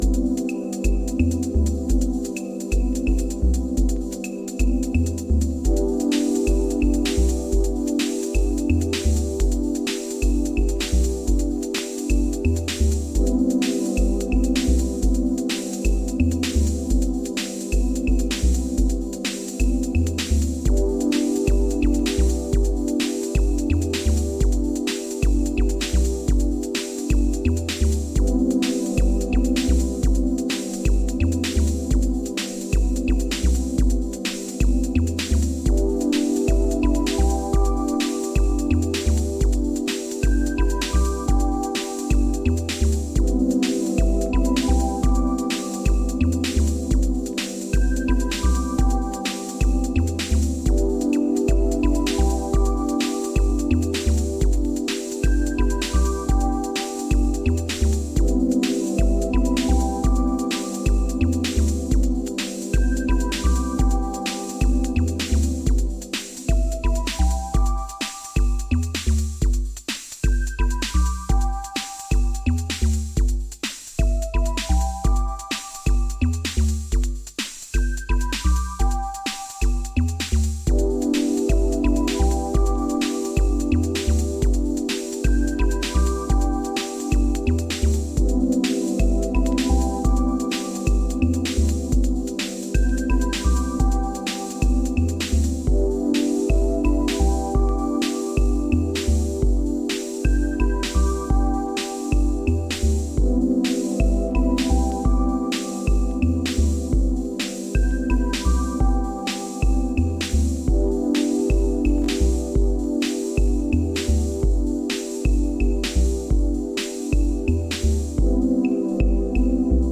Lovely house tracks